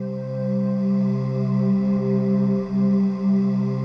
PAD 50-3.wav